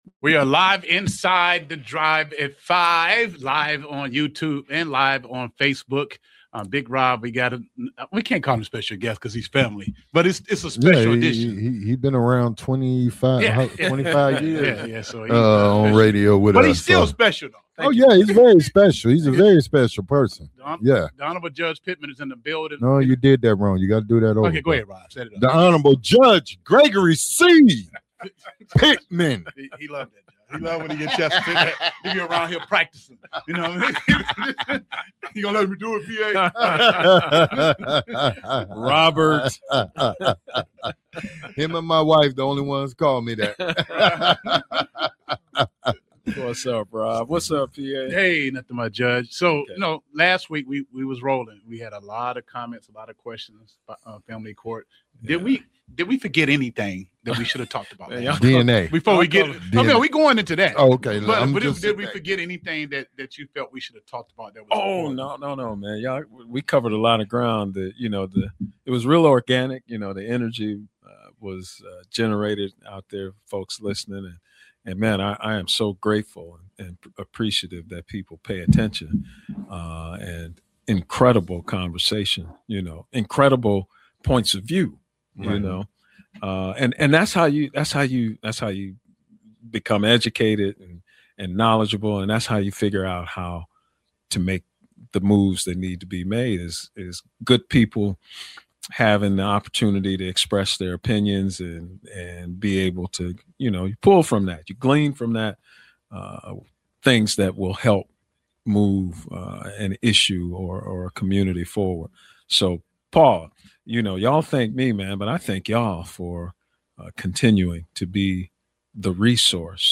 Interview with The Honorable Judge Gregory C Pittman